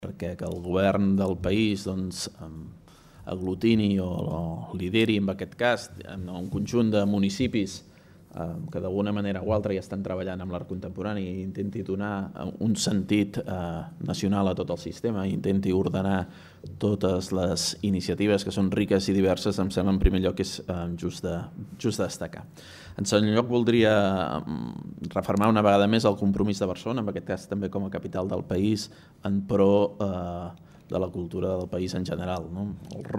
Àudio: Declaracions del tinent d'alcalde de Cultura, Coneixement i Innovació de l'Ajuntament de Barcelona, Jaume Ciurana